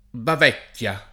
Bavecchia [ bav $ kk L a ] top. (Tosc.)